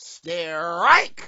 wack_strrrrrrike.wav